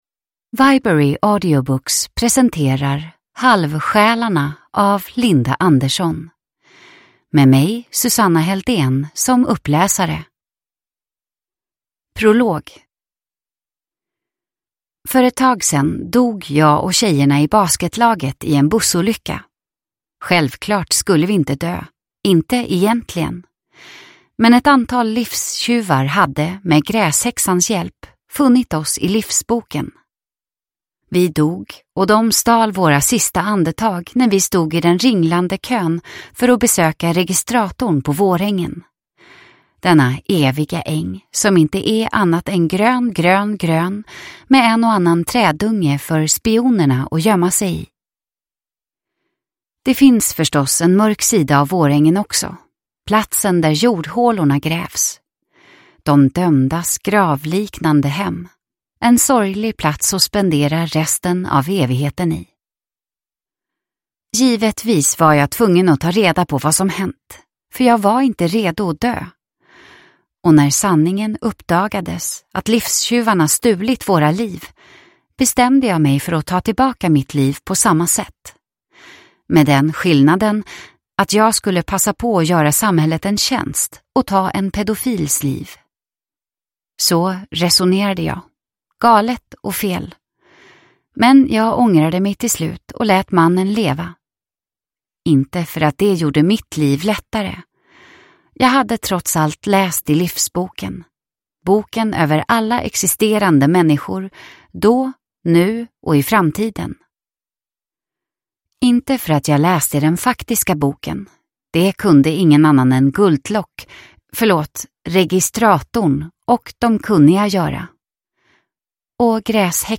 Halvsjälarna (ljudbok) av Linda Andersson